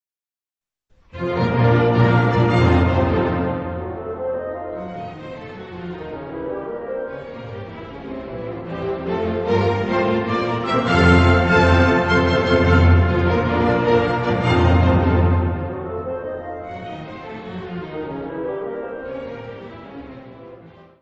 Área:  Música Clássica
Menueto. Allegro vivace.